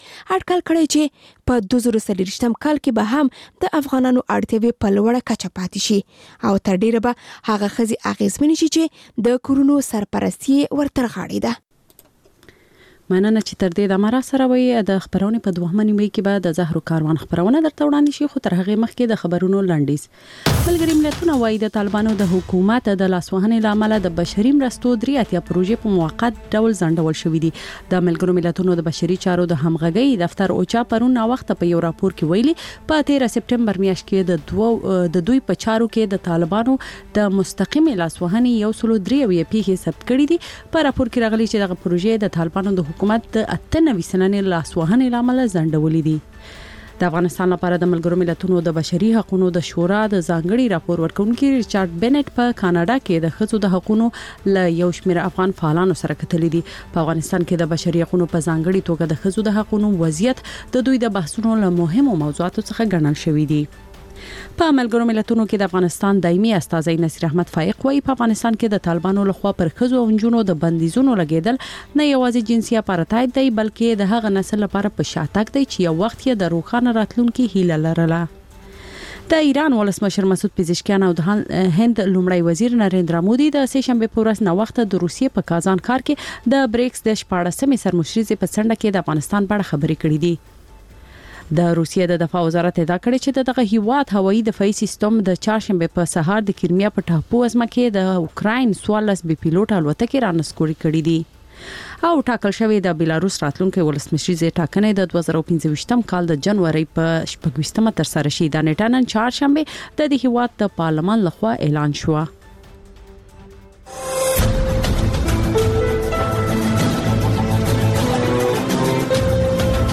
لنډ خبرونه - د زهرو کاروان (تکرار)